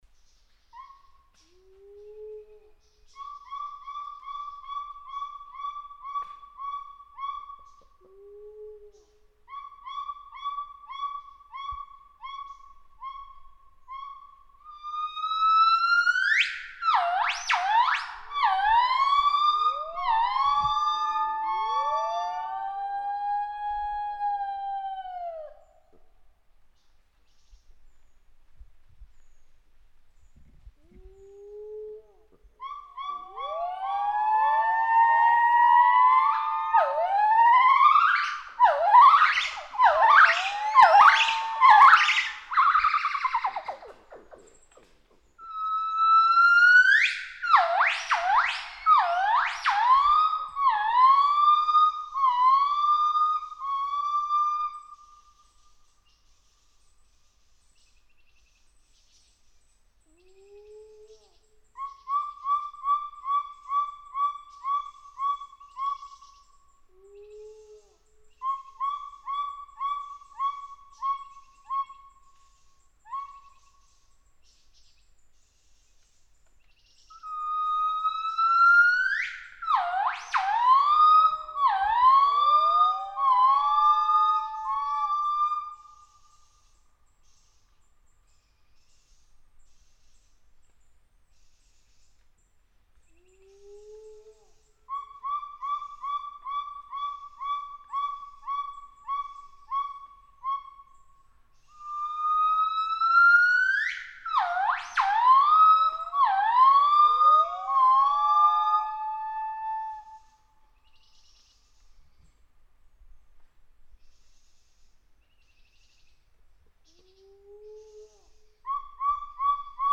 每天清晨，在无量山深处的大寨子西黑冠长臂猿监测站，都会听到西黑冠长臂猿嘹亮悠长的鸣叫，在没有山体遮挡的情况下鸣叫声可以传播2—3公里。
（音频）西黑冠长臂猿鸣叫声